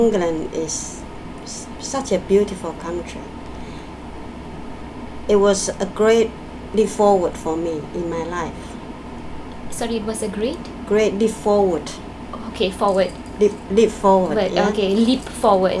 S1 = Malaysian female S2 = Taiwanese female Context: S1 has asked S2 about her life in England.
S1 is caught out by this expression, partly because S2 omits the [p] at the end of leap . Eventually S1 gets it and then says leap with a clearly articulated final [p], to indicate she understands it.